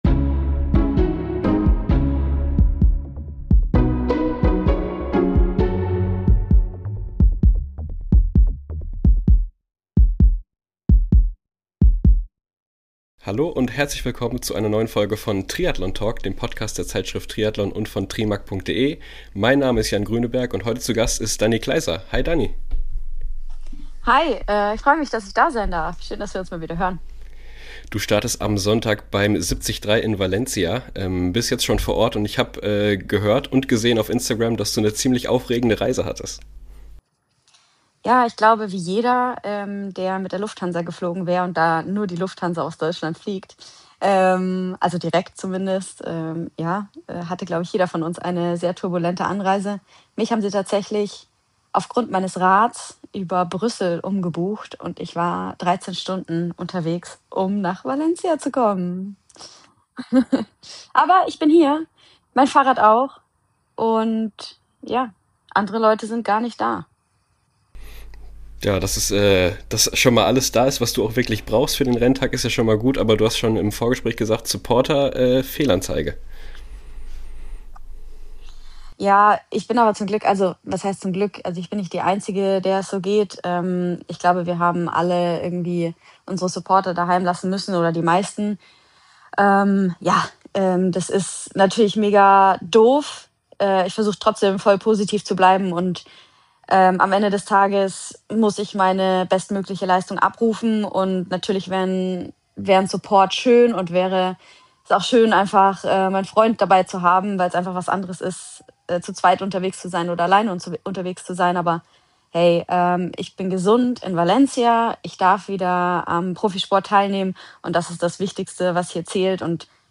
Im Gespräch erzählt sie, wie sich der Körper in dieser Zeit verändert hat, warum der Wiedereinstieg mental oft härter war als die Pause selbst – und weshalb sie heute früher auf Warnsignale hört.